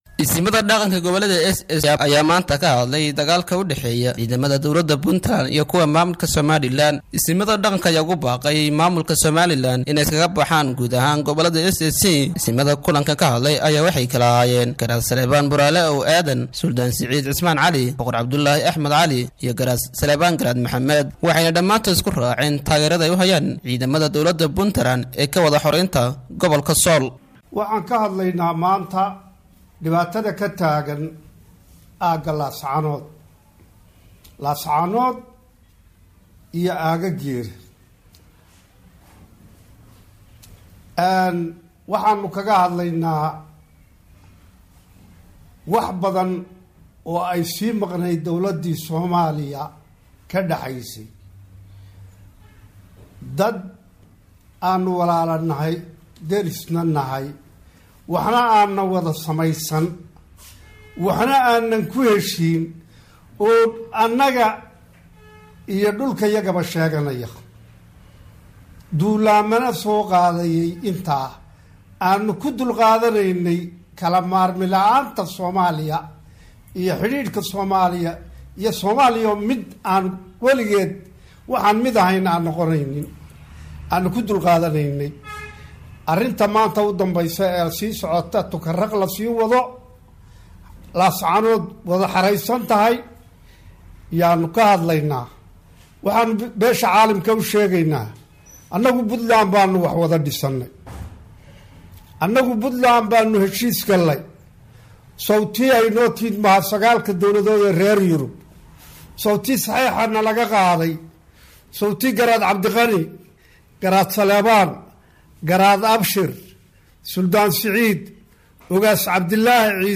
30 May 2018 (Puntlandes) Qaar ka mid ah Isimada Bee laha Dhulbahante oo kullan ku yeeshay magaalada Buuhoodle ayaa taageeray qor shaha ay dawladda Puntland uga xoraynayso maamulka Somaliland.